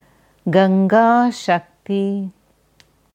Ganga Shakti गङ्गाशक्ति gaṅgāśakti Aussprache
Hier kannst du hören, wie das Sanskritwort Ganga Shakti, गङ्गाशक्ति, gaṅgāśakti ausgesprochen wird: